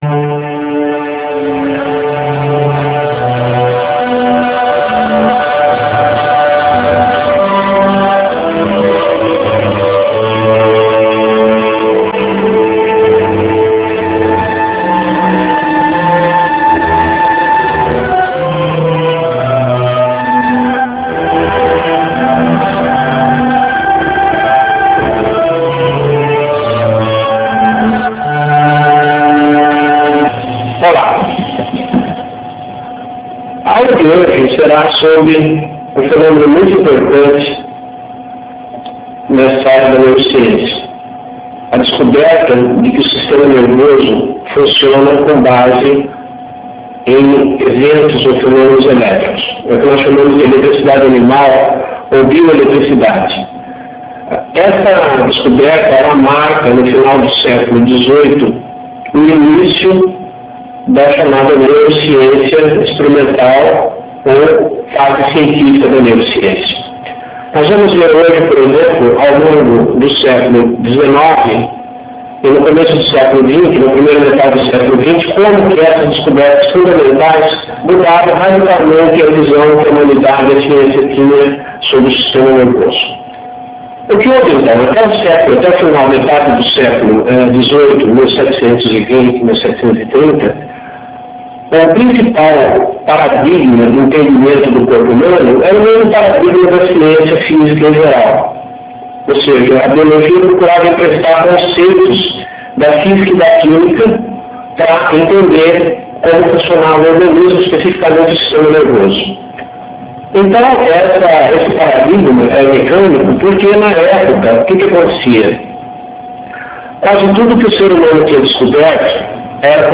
Assista a aula expositiva em rempo real, no formato de vídeo em RealMedia disponível no site do curso.